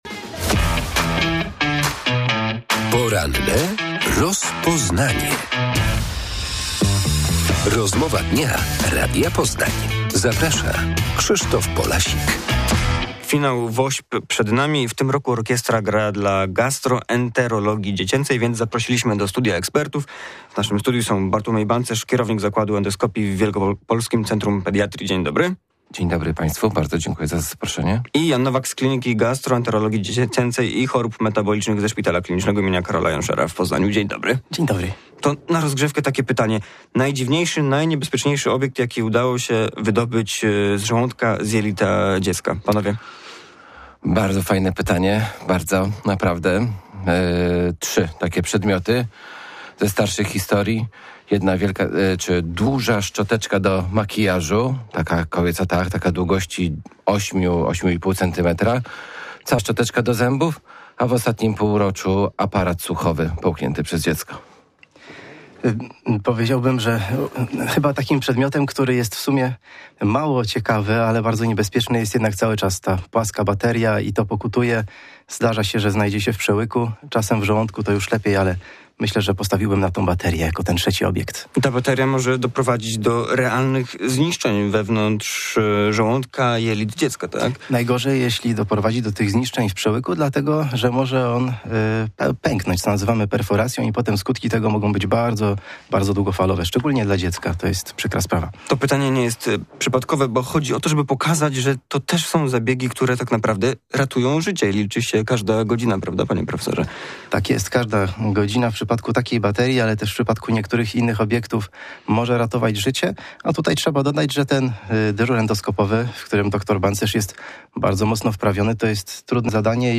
To sprzęt ratujący życie i pomagający w wieloletnim leczeniu. W tym roku Wielka Orkiestra Świątecznej Pomocy wspiera gastroenterologię dziecięcą. Goście Porannej rozmowy Radia Poznań tłumaczyli, że endoskopy są wykorzystywane między innymi do wydobywania przedmiotów połkniętych przez dzieci, takich jak zabawki czy nawet szczoteczki do zębów.